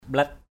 /ɓlɛt/